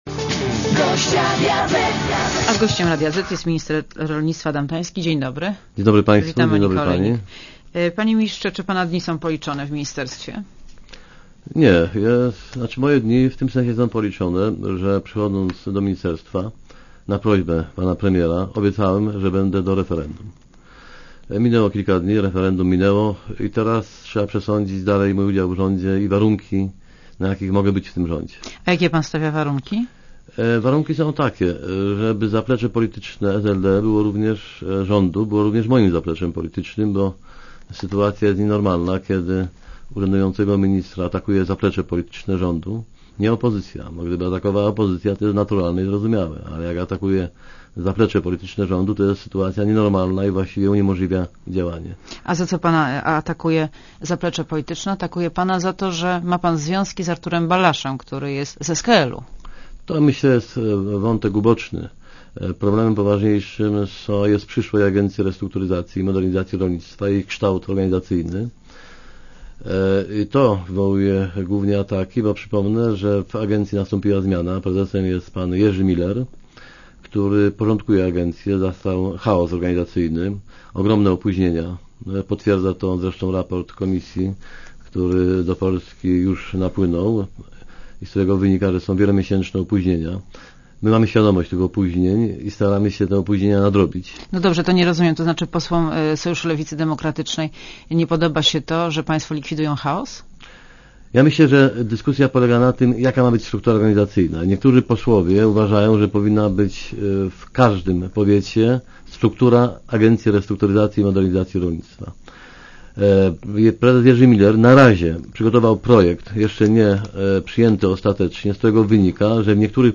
© (RadioZet) Posłuchaj wywiadu (2,6MB) Panie Ministrze, czy pana dni są policzone w ministerstwie?